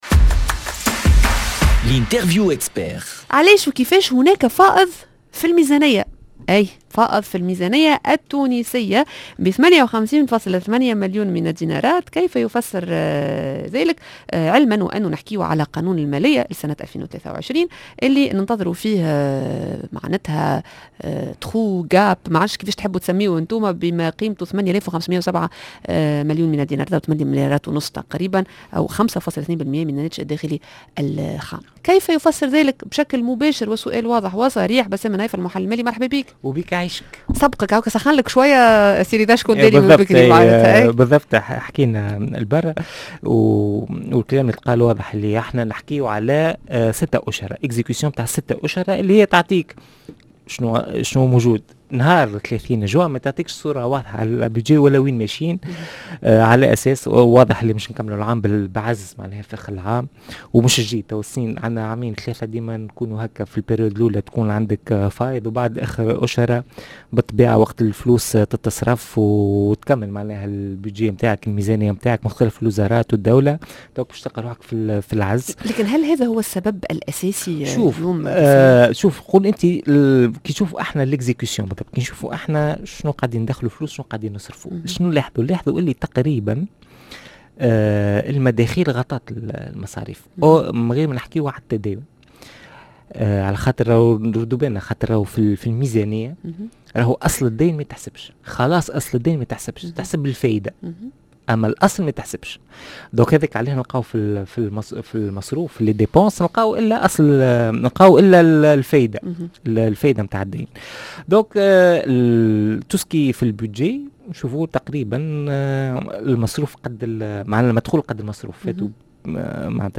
#L'interview expert